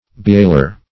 Bewailer \Be*wail"er\, n. One who bewails or laments.